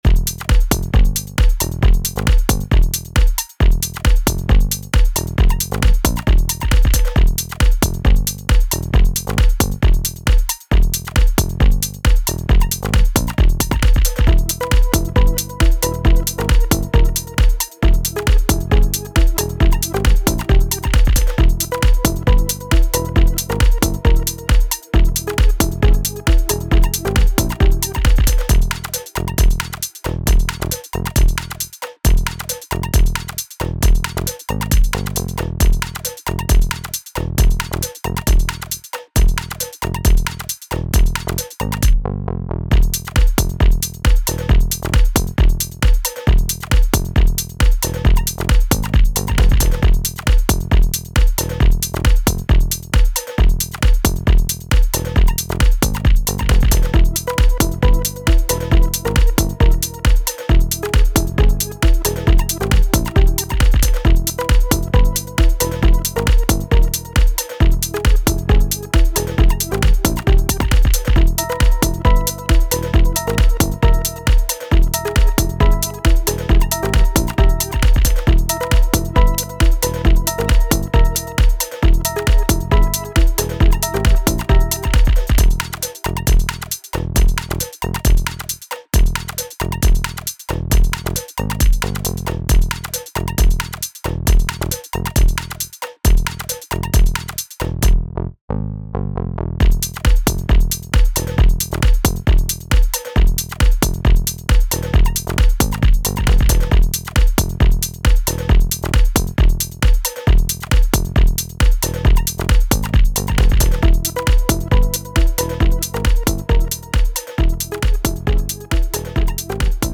タグ: Beat EDM 変わり種 怪しい 暗い 電子音楽 コメント: 無機質で硬派な電子音楽。